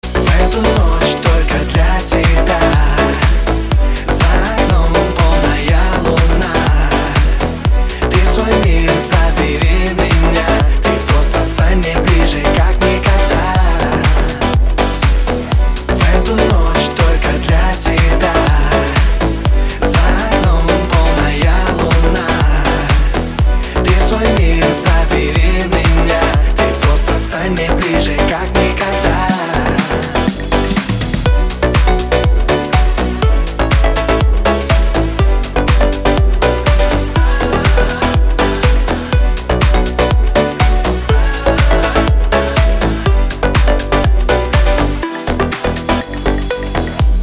мужской вокал
dance
Electronic
Club House
электронная музыка